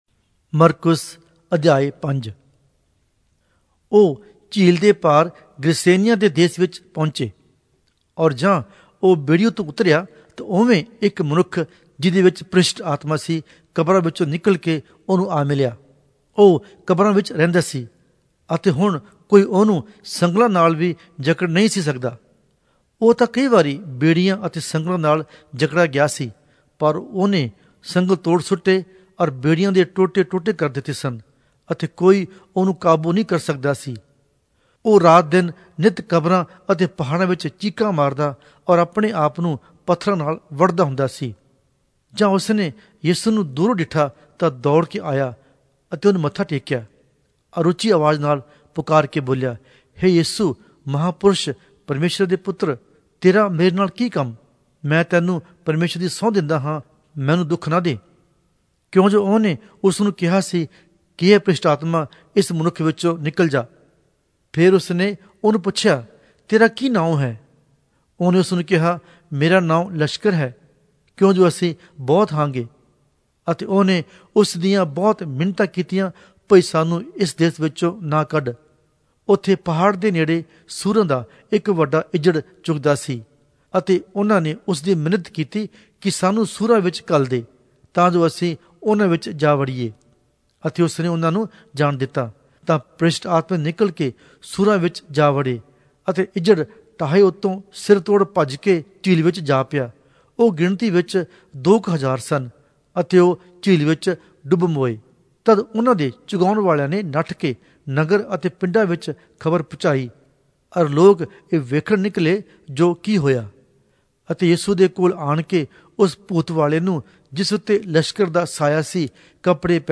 Punjabi Audio Bible - Mark 5 in Hcsb bible version